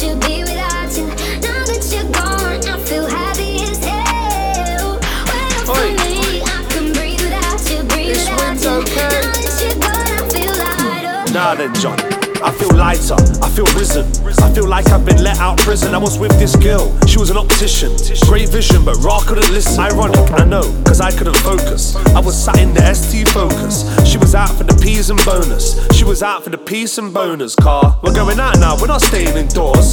• Dance